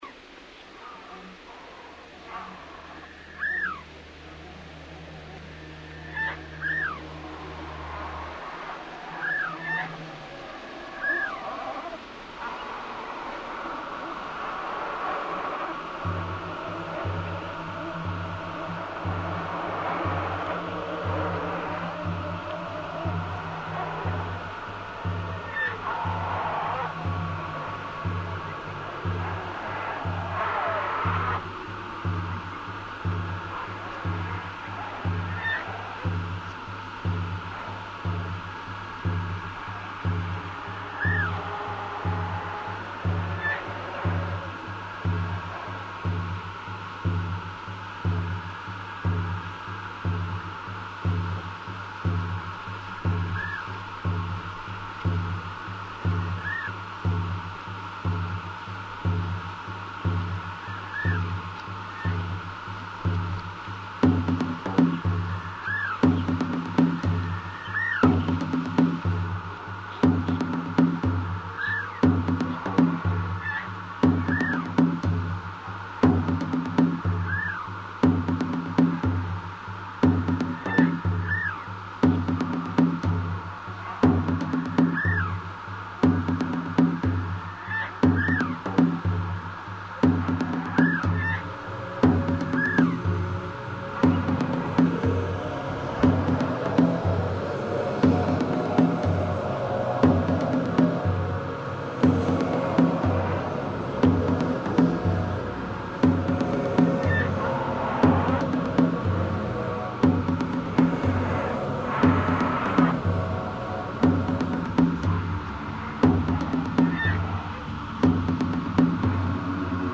more upbeat and pop than any of my previous music